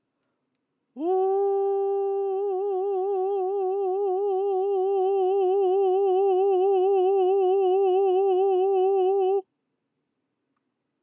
発声しながら喉頭を下げる
音量注意！
出し始めは特に喉頭の位置を操作していない声で、そこから徐々に下げています。